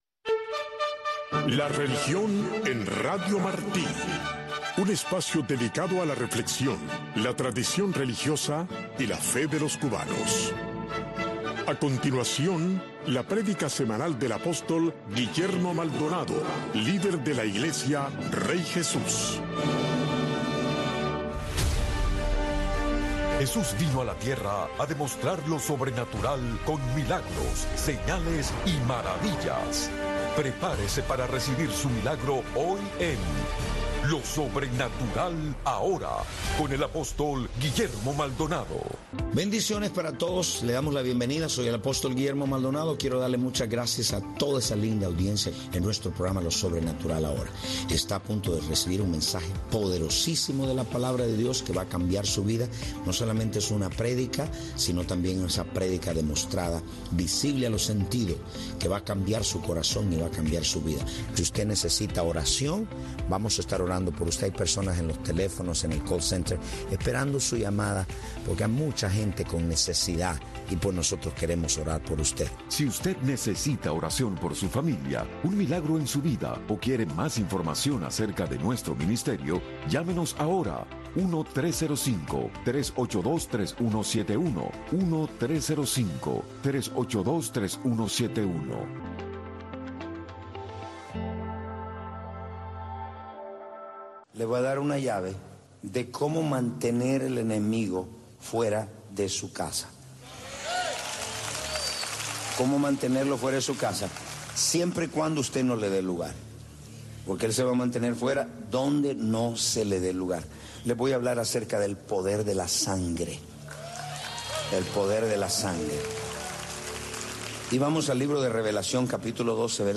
Radio Martí te presenta todos los sábados y domingos entre 6 y 7de la mañana el bloque religioso “La Religión en Martí” en donde te presentaremos diferentes voces de académicos, pastores y hombres de fe que te traerán la historia y la palabra esperanzadora del señor.